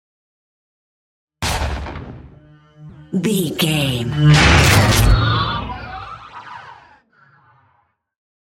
Double hit with whoosh large sci fi
Sound Effects
dark
futuristic
intense
woosh to hit